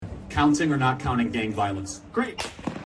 counting or not counting gang violence Meme Sound Effect
Category: Meme Soundboard